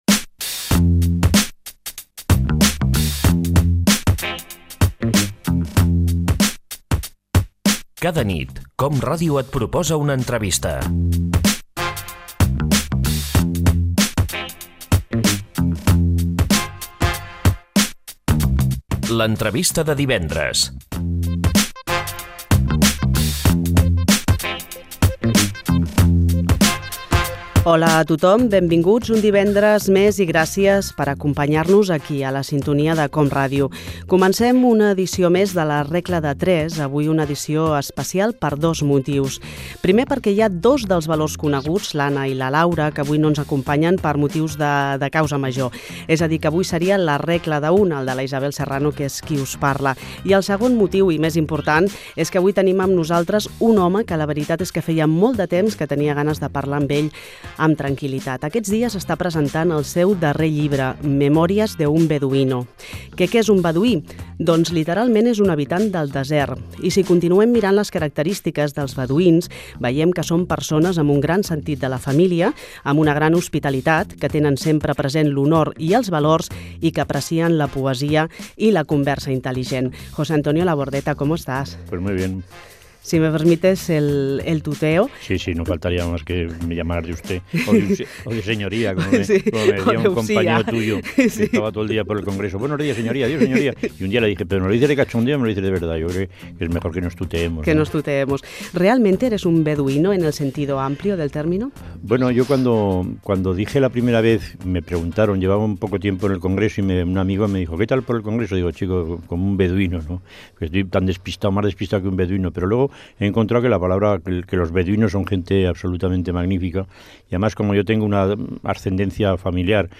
Careta, presentació i entrevista al cantant i polític José Antonio Labordeta
FM